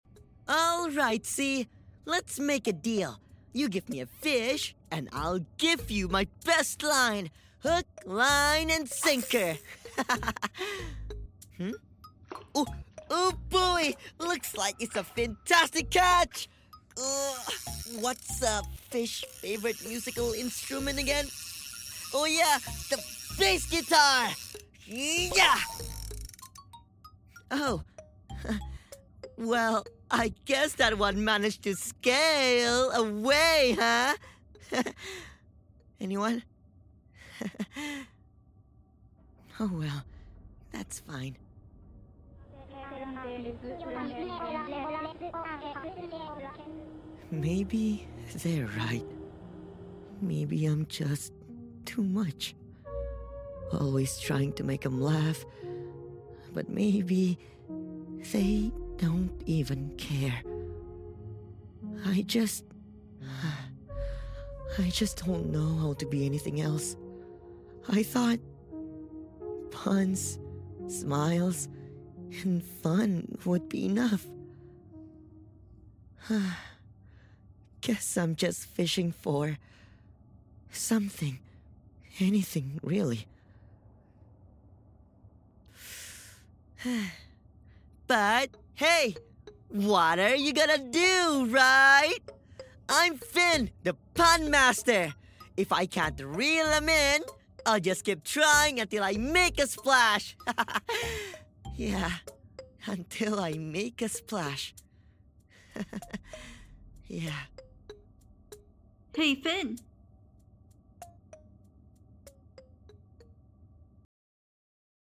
Voice acting finn